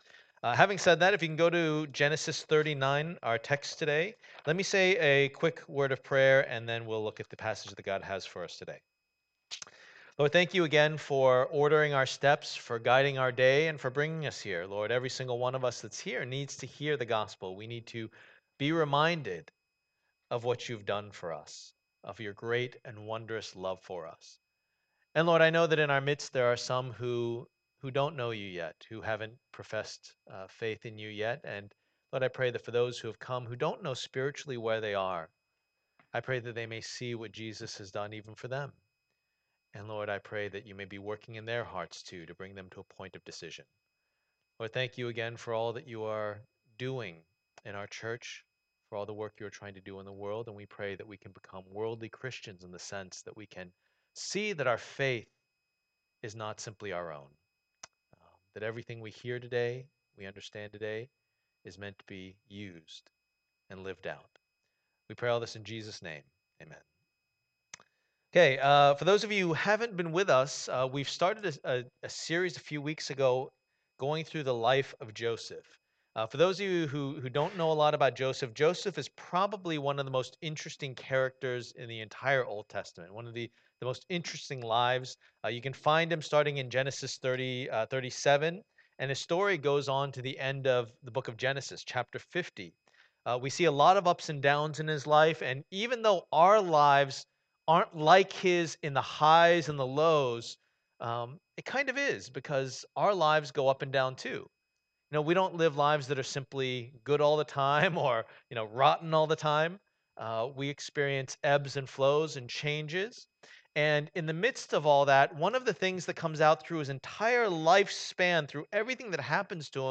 A Life Lost and Redeemed Passage: Genesis 39:1-23 Service Type: Lord's Day %todo_render% « Is God Really Sovereign?